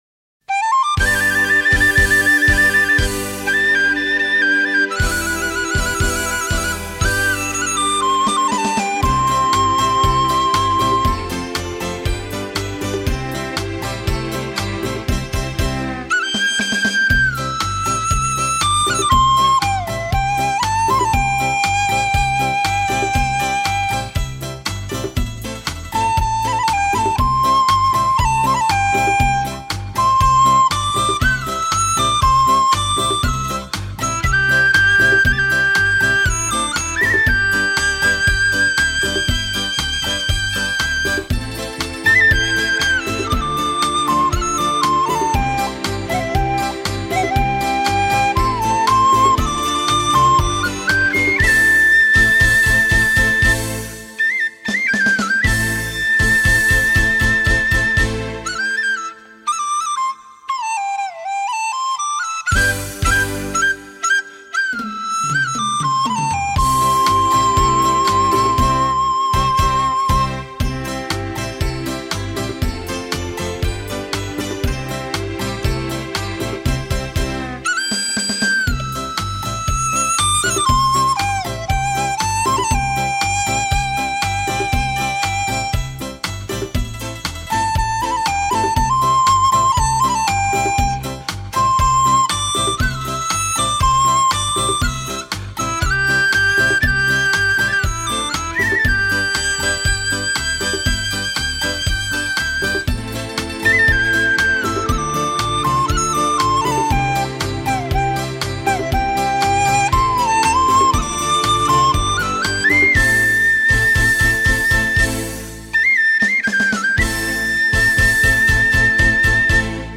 笛子演奏